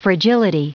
Prononciation audio / Fichier audio de FRAGILITY en anglais
Prononciation du mot fragility en anglais (fichier audio)